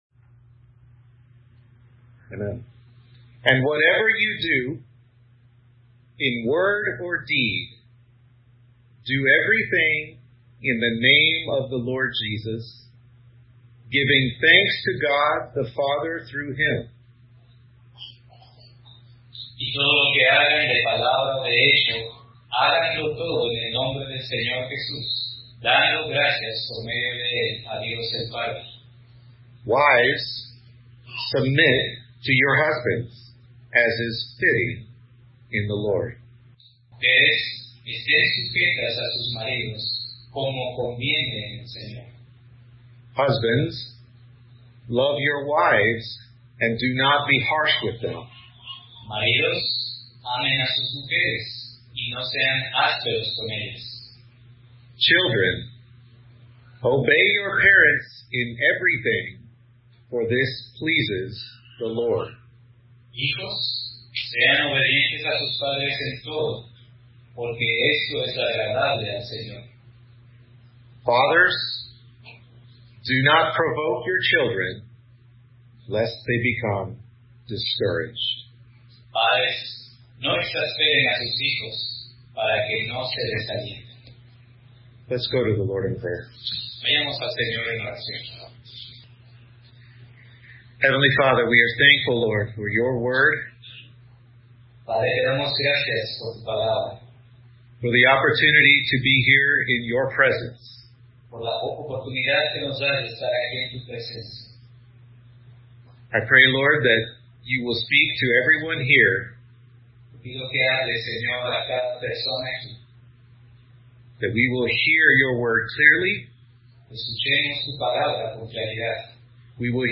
Colossians 3:17-21 The Christian Family Iglesia Pacto de Gracia Cd. Juarez, Mexico